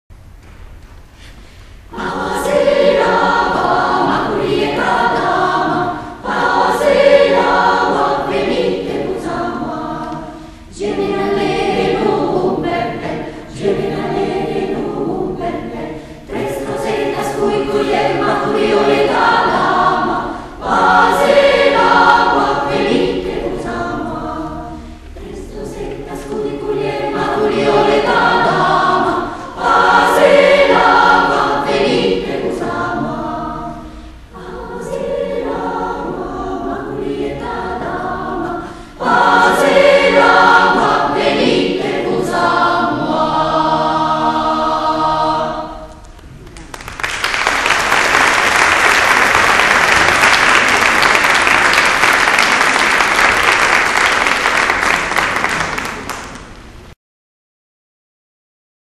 Concerto di Primavera
Sala Maestra ~ Palazzo Chigi
Coro in Maschera
Eseguiti in Ensamble